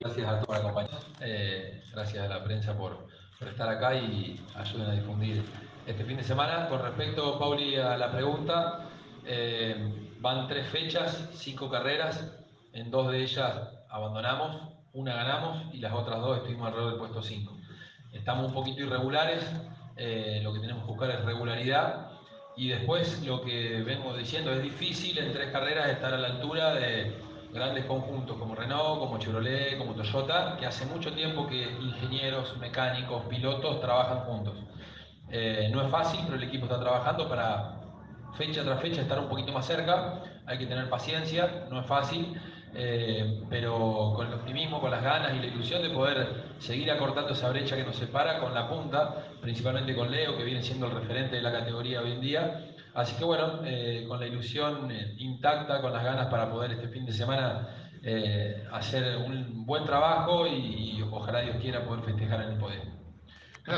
El piloto de Las Parejas estuvo presente en la conferencia de prensa en Rosario, donde se presentó la competencia que disputará este fin de semana la cuarta fecha del calendario para el TC2000.